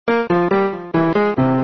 piano nē 79
piano79.mp3